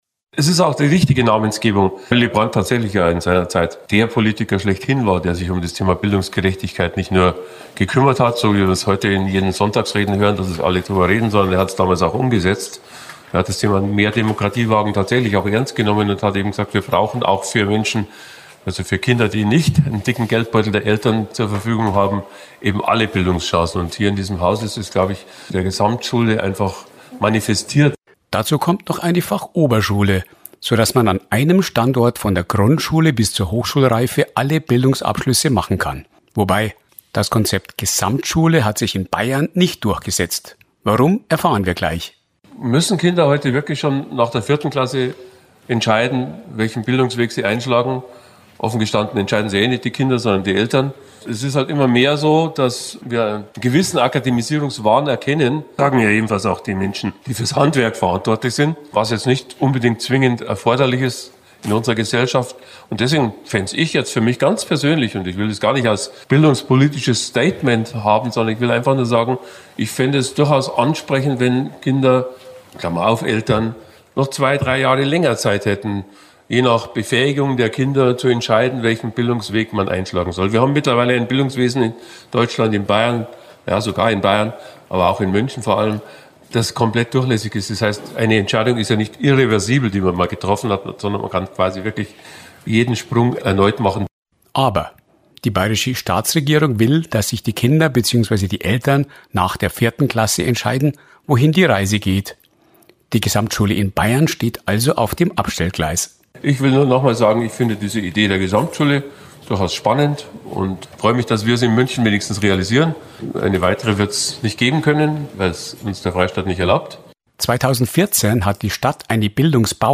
Gesamtschule: In ganz Bayern gibt es davon gerade mal zwei, in München eine. Warum das so ist, erklärt OB Dieter Reiter in seiner kurzen Ansprache beim Pressetermin vor Ort. Doch vorher sagt er was zum Namensgeber der Schule: Willy Brandt.